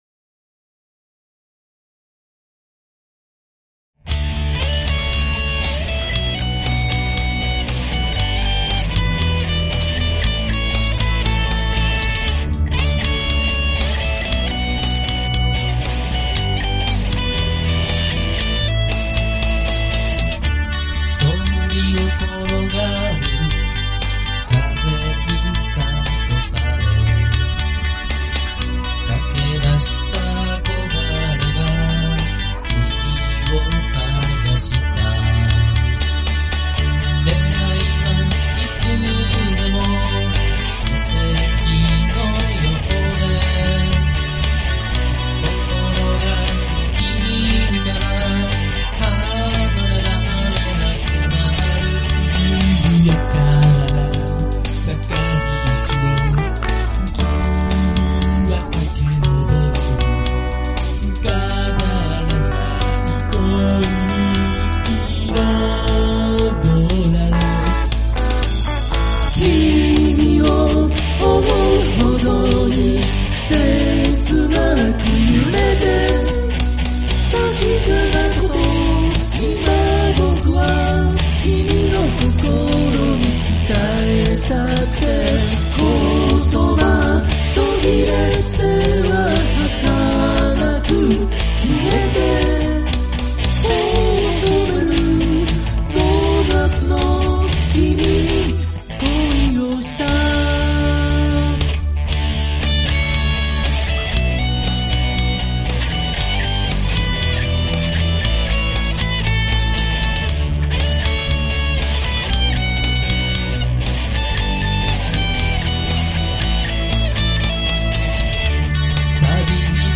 Vocal,A.guitar
Chorus,Bass,A.guitar,E.guitar,Keyboard,Drums
ロックチューンです。